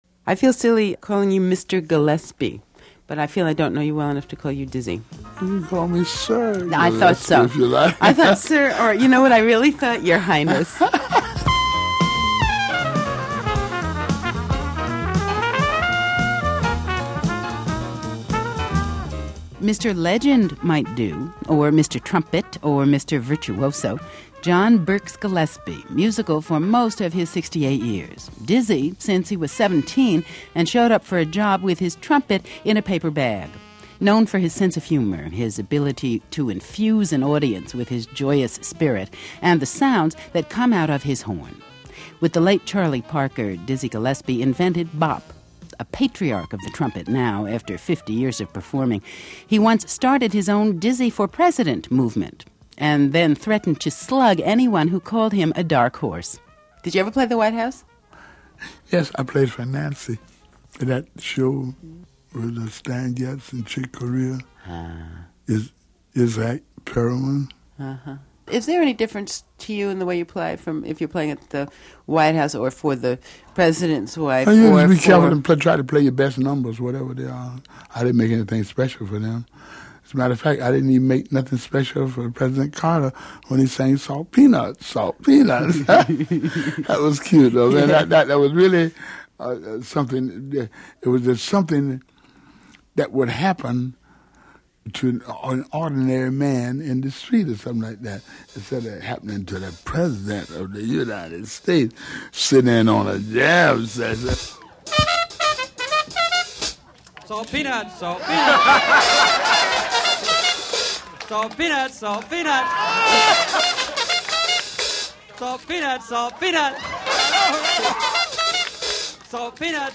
That's when host Susan Stamberg spoke with the trumpeter, composer and overall jazz legend.